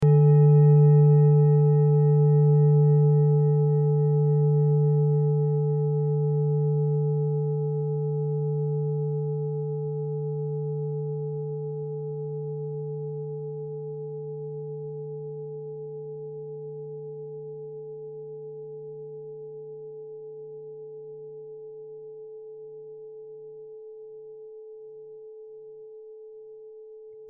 Klangschalen-Typ: Tibet
Klangschale Nr.6
Gewicht = 1430g
Durchmesser = 22,4cm
(Aufgenommen mit dem Filzklöppel/Gummischlegel)
klangschale-set-6-6.mp3